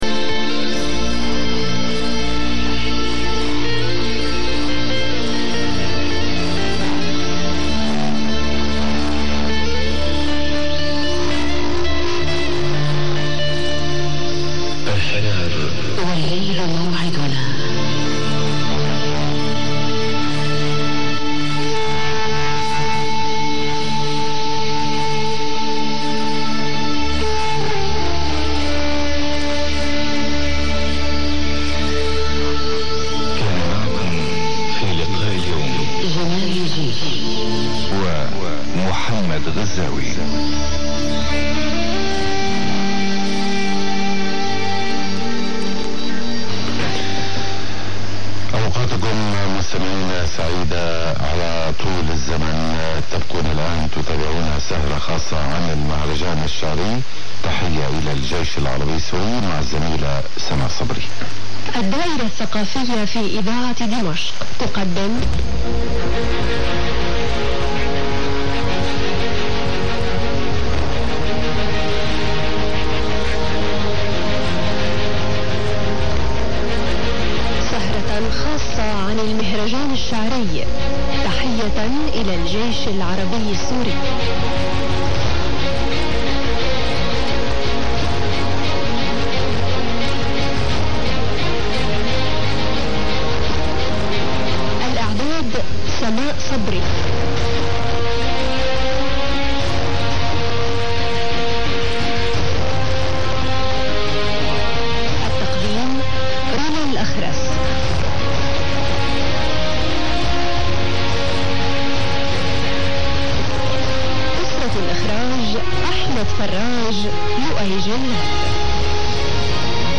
Szólna ez a technika, ha nem lenne hanggal túlvezérelve...
Idaat al Dimasq - 1:03 ID - 2:55-től mi ez az induló? - nem a hivatalos himnusz - de gyakran hallani a szír rádióban...